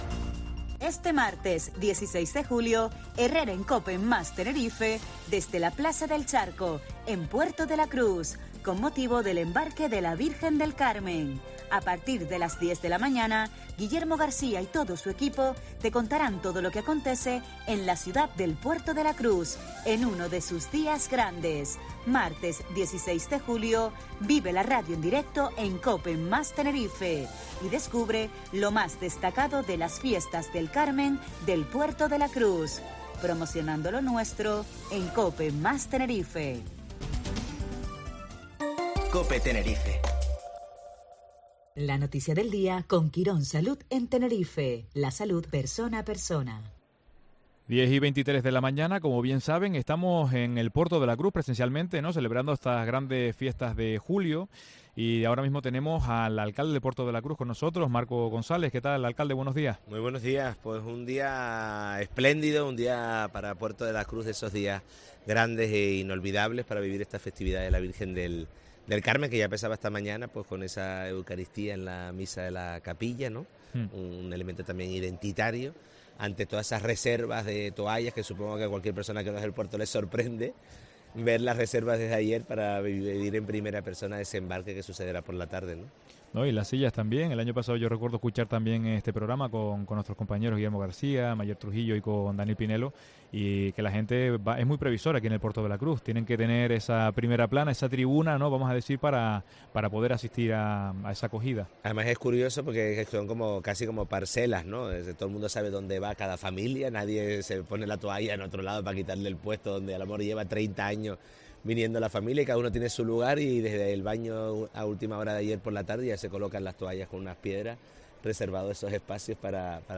El alcalde de Puerto de la Cruz, en COPE Canarias en la Embarcación de la Virgen del Carmen